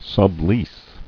[sub·lease]